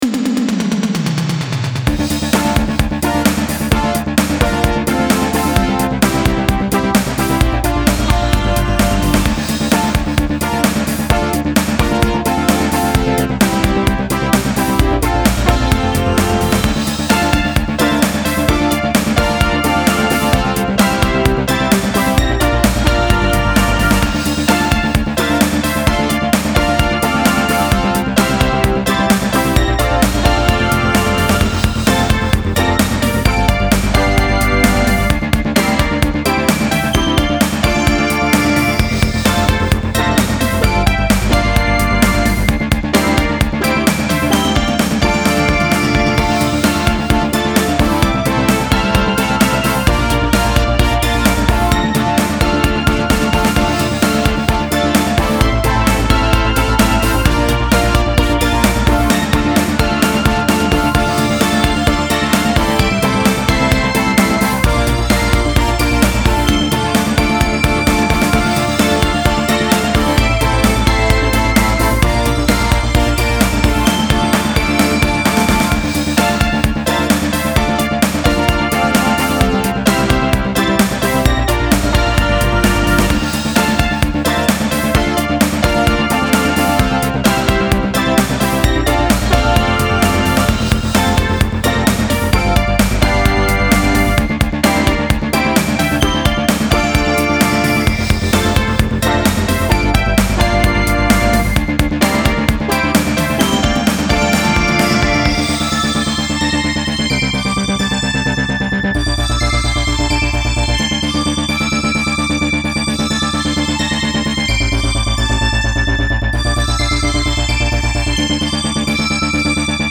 Style Style EDM/Electronic, Pop
Mood Mood Driving
Featured Featured Bass, Drums, Synth
BPM BPM 130
It even fades out...